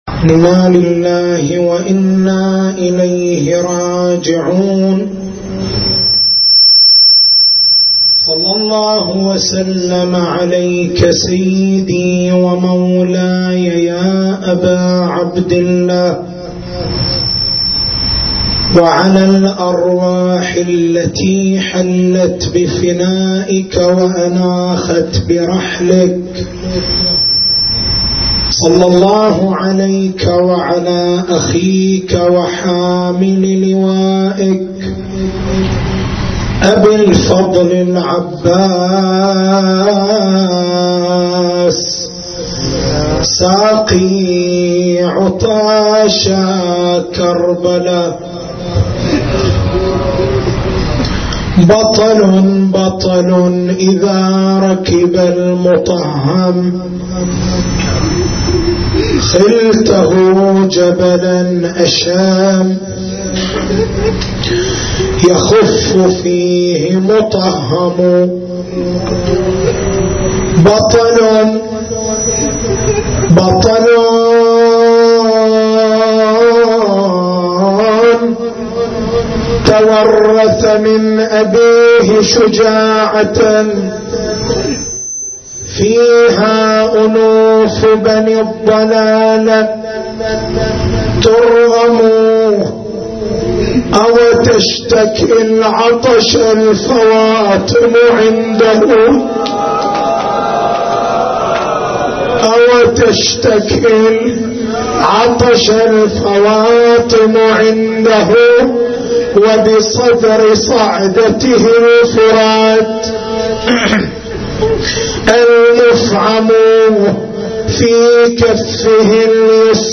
حسينية الزين بالقديح